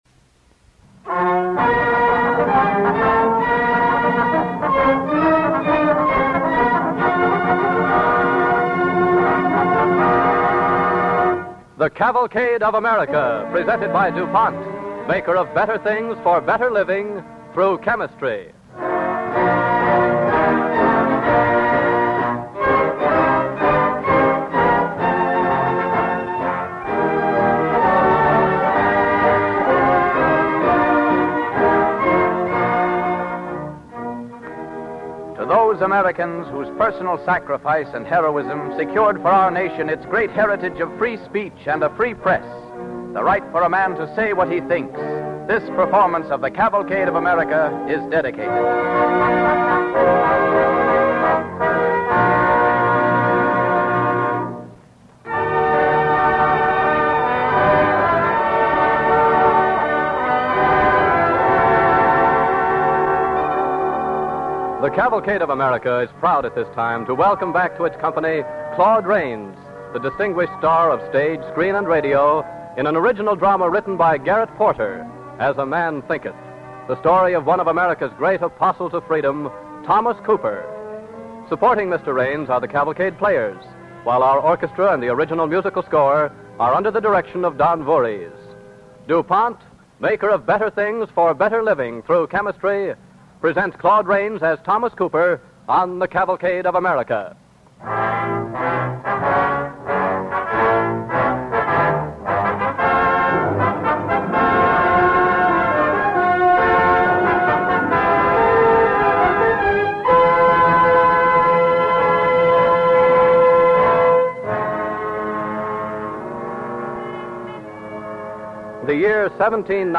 starring Claude Rains